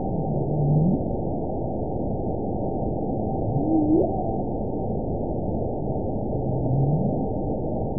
event 919026 date 12/23/23 time 12:30:05 GMT (1 year, 4 months ago) score 7.42 location TSS-AB05 detected by nrw target species NRW annotations +NRW Spectrogram: Frequency (kHz) vs. Time (s) audio not available .wav